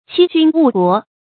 欺君誤國 注音： 讀音讀法： 意思解釋： 欺騙君主，禍害國家。